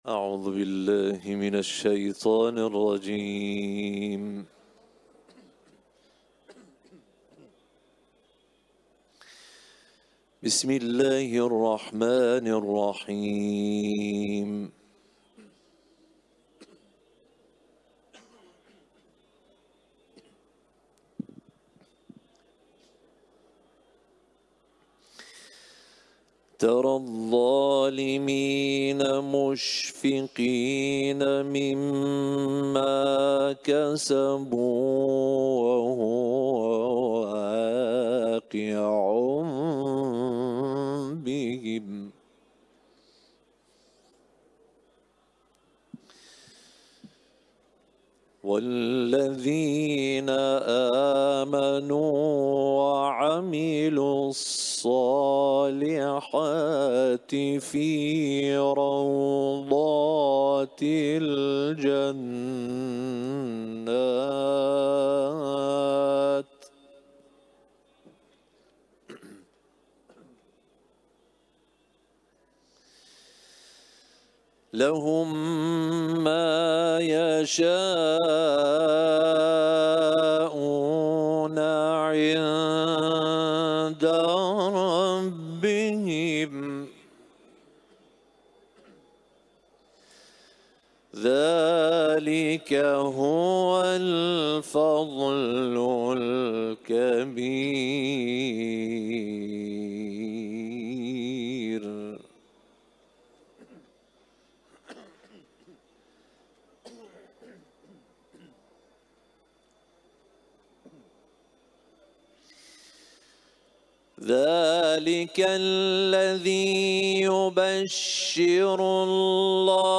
تلاوت